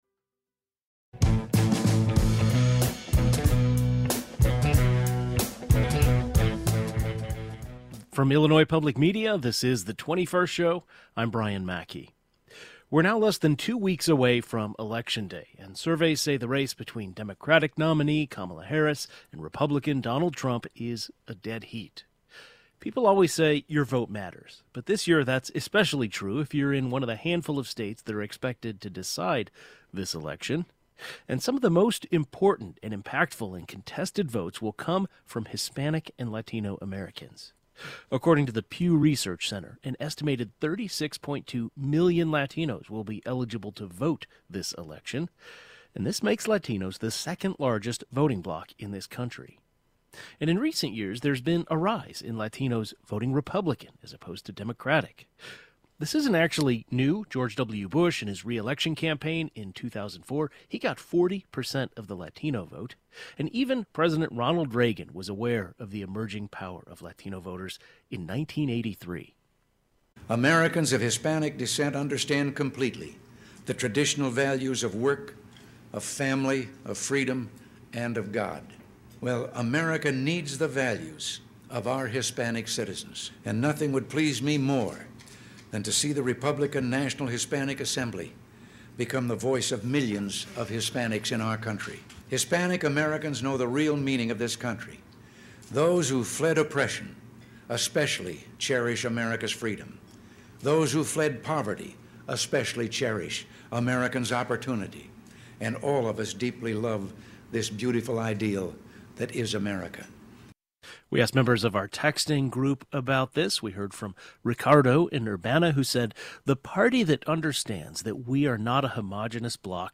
In recent years, there has been a rise in Latinos voting for Republicans, as opposed to Democrats. A panel of experts including a professor specializing in Latino studies, a representative from the Pew Research Center, and a political analyst weigh in on this shift in the Latino community.